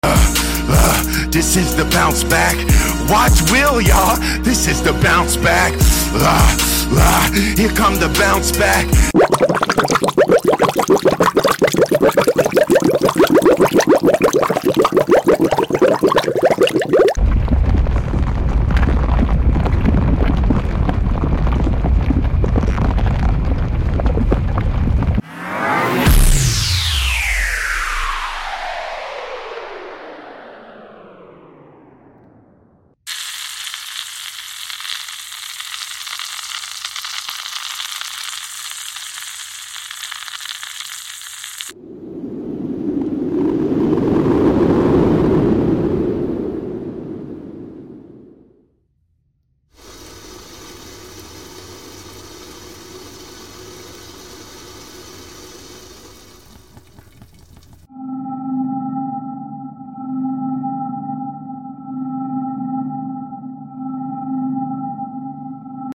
Recreating AI ASMR AI Ice Sound Effects Free Download